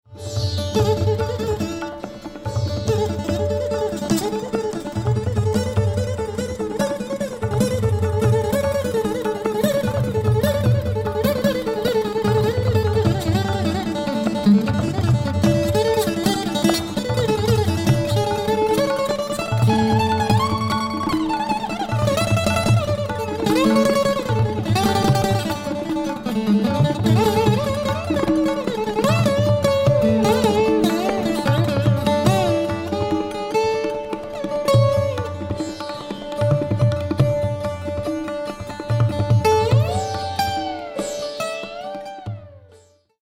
A prominent early afternoon raga of the Sarang family, often said to evoke sringara.
Aroha: NSRMPNS
Avroh: SN; DPMPM; mRS; NS
Pakad: e.g. NDSNRS
—Debashish Bhattacharya (2013)—
• Tanpura: Sa–Pa (+Re)
AUD2-Shuddha-Sarang-Debashish-Bhattacharya.mp3